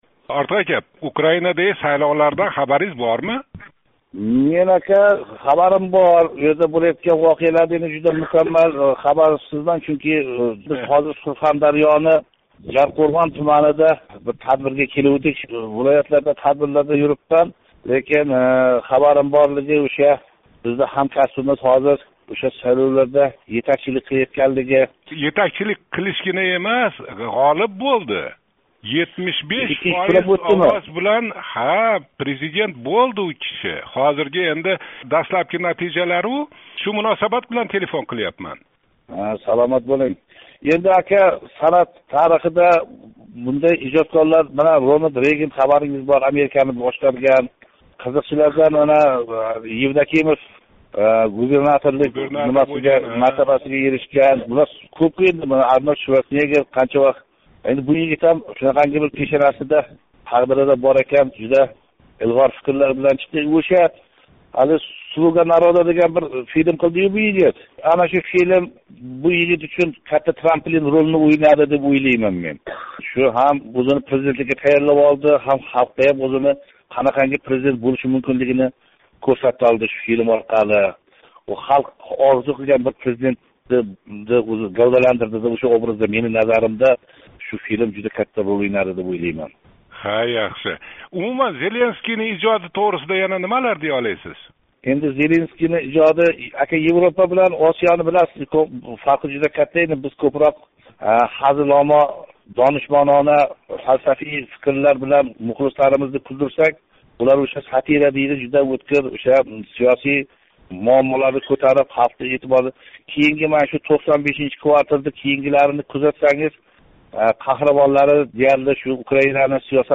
Украиналик ҳамкасбининг муваффақиятига муносабат билдиришини сўраб, таниқли ўзбек қизиқчиси Ортиқ Султоновга мурожаат қилдик. Қизиқчини Сурхондарёнинг Жарқўрғонидан топдик.